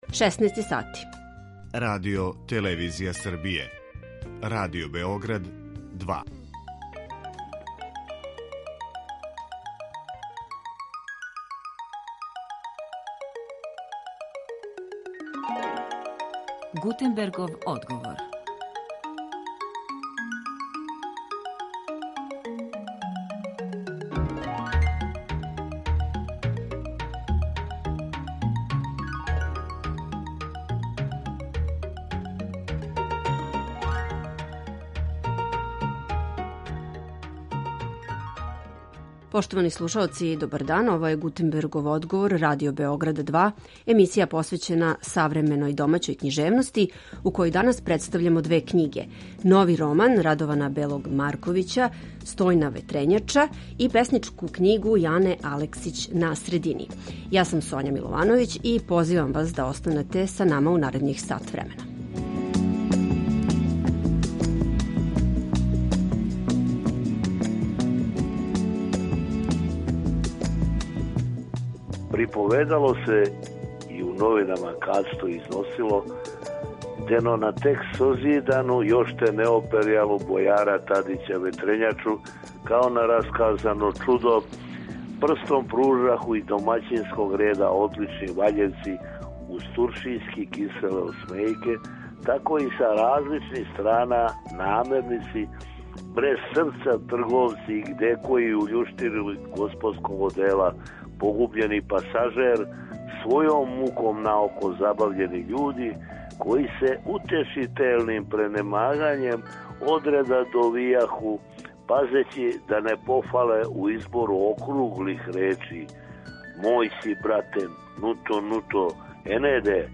Гости Гутенберговог одговара су писац Радован Бели Марковић